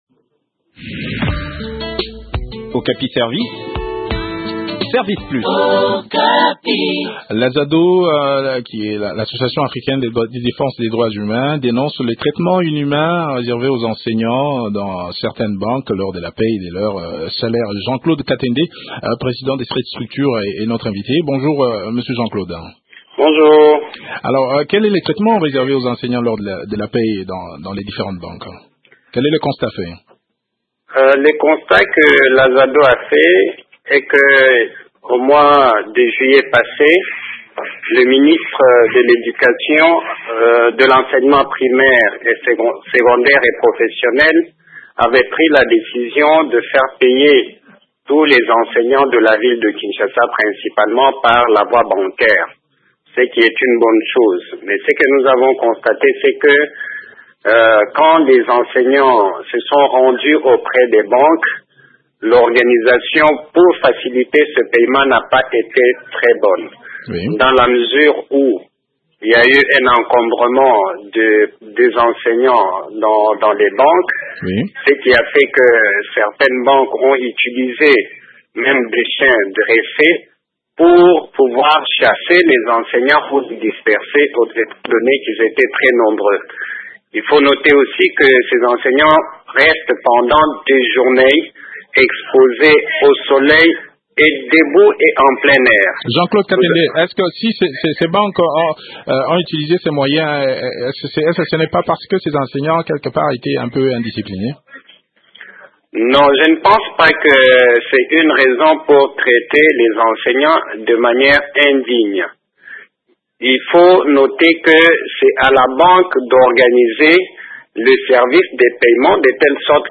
cet entretien